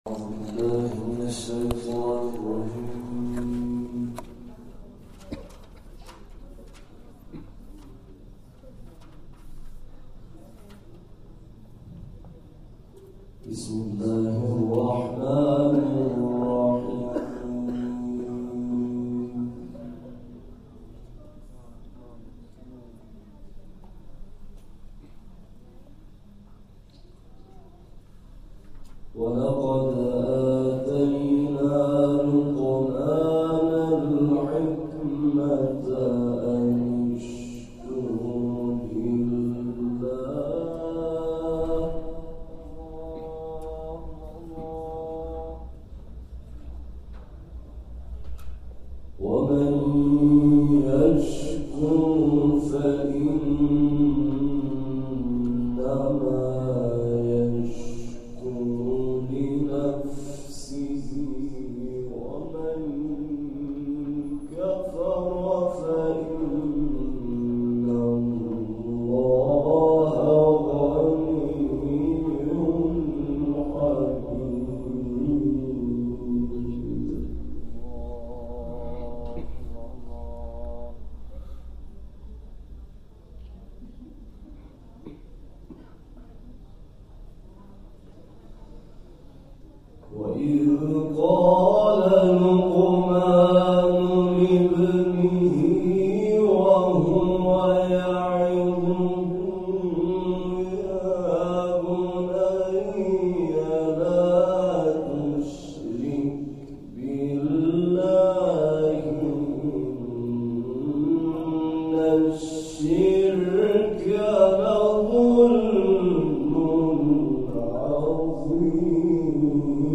روش اداره این جلسه به این صورت است که قاریان، متناسب با سطح تلاوت خود از تلاوت‌های قاریان مصری همچون منشاوی، عبدالباسط و مصطفی اسماعیل تقلید کرده و در جلسه ارائه می‌کنند. همچنین، حافظان این جلسه قرآن، بصورت هفتگی قسمت معینی را تمرین و در جلسه ارائه می‌کنند.